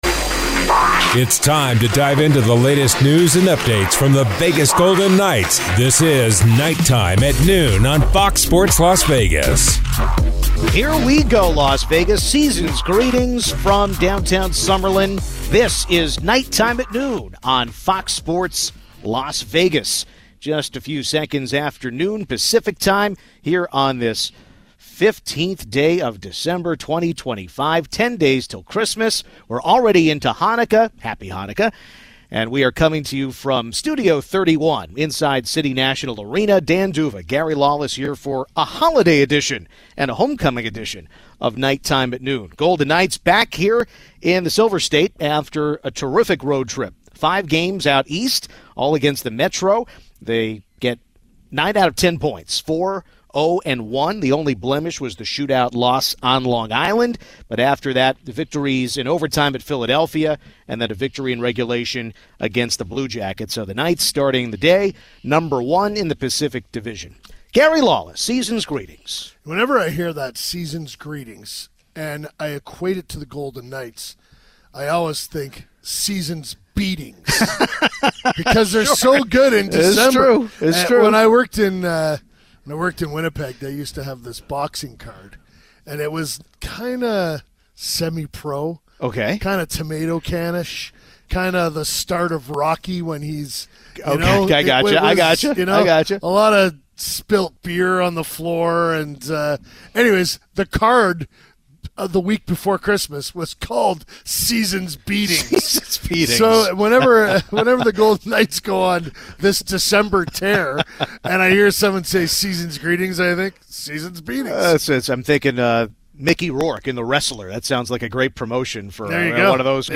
Mike Kelly (R-PA16) on the phone to discuss affordability, tariffs, and what Erie-area constituents are feeling here at year-end.